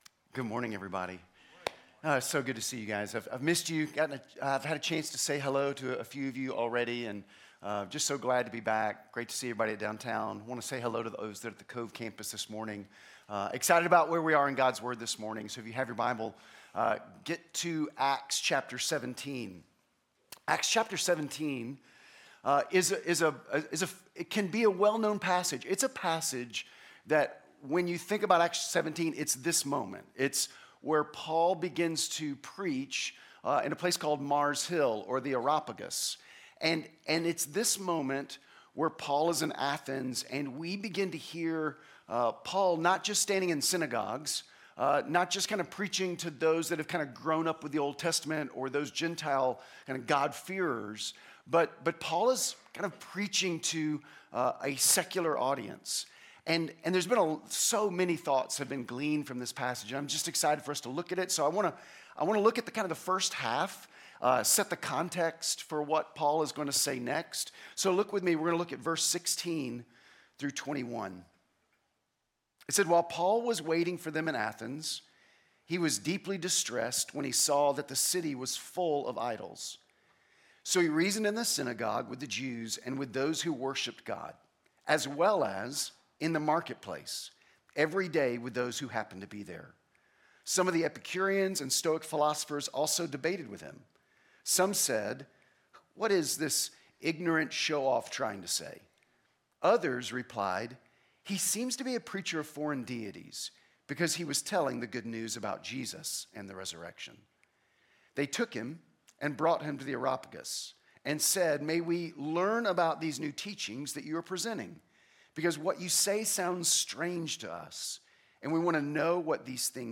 Sermon Audio Sermon Notes…